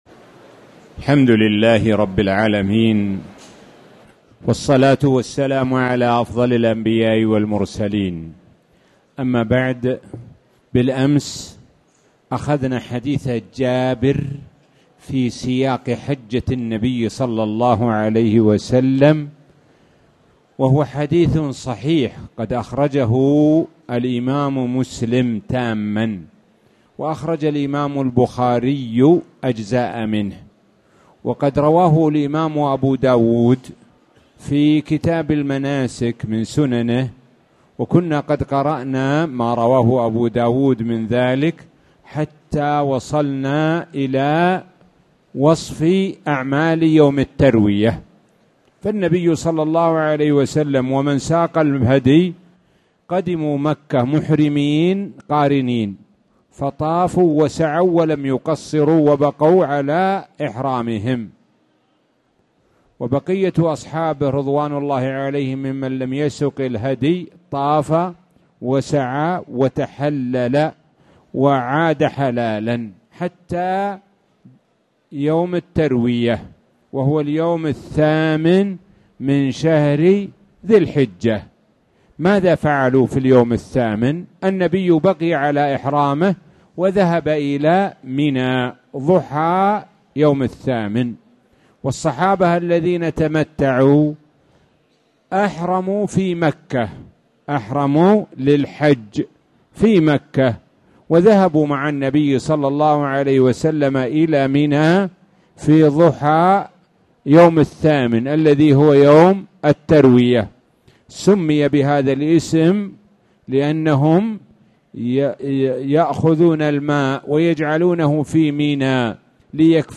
تاريخ النشر ٢ ذو الحجة ١٤٣٨ هـ المكان: المسجد الحرام الشيخ: معالي الشيخ د. سعد بن ناصر الشثري معالي الشيخ د. سعد بن ناصر الشثري أعمال يوم التروية